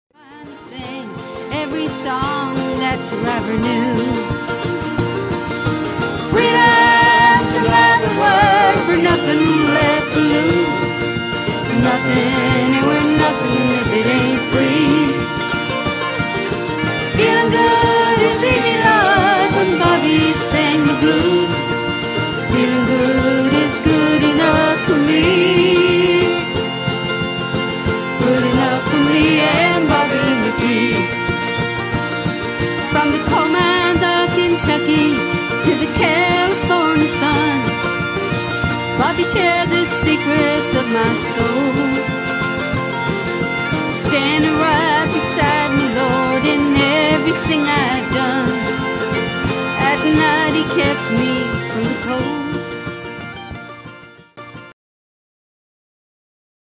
From humble beginnings as a Pub Band in 2001, this Chicago Irish Band has emerged as an exciting fiery group, performing time honored Irish music and songs including a fun filled blend of folk, country and occasional soft rock favorites.
accompanied by guitars, fiddle, mandolin, penny whistles, harmonica, djembe, bodhrans and bag pipes